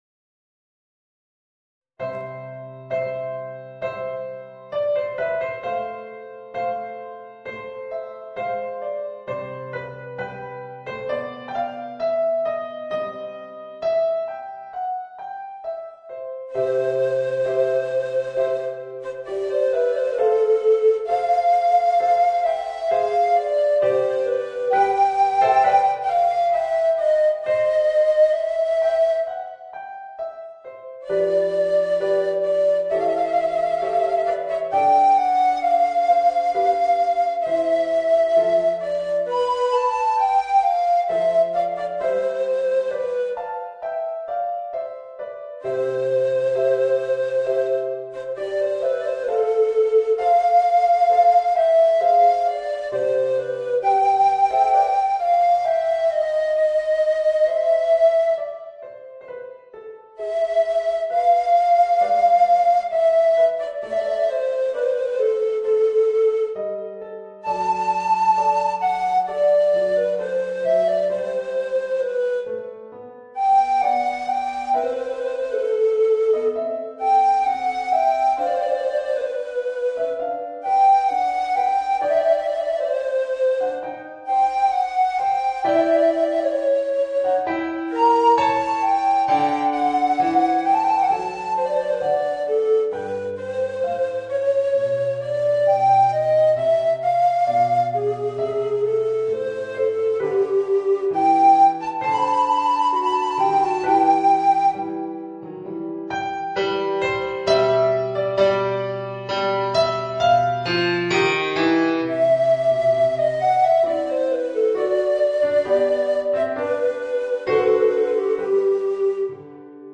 Voicing: Tenor Recorder and Organ